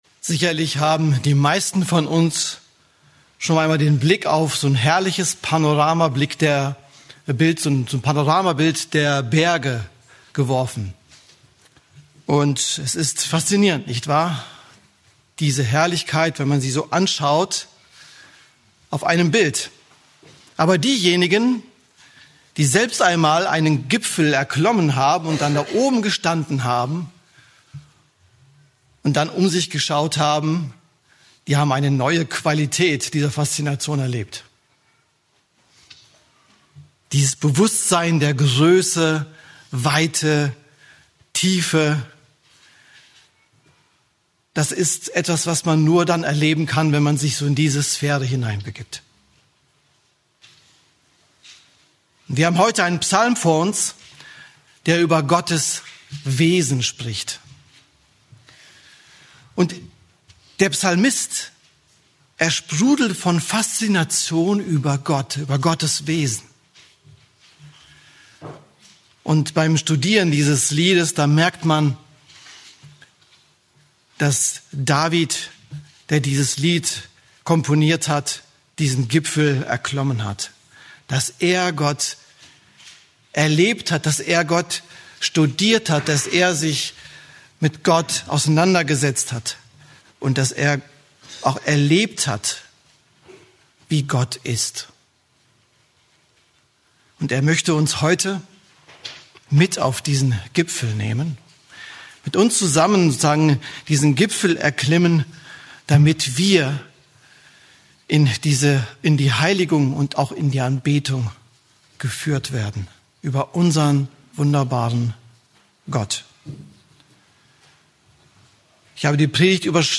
Eine predigt aus der serie "Einzelpredigten."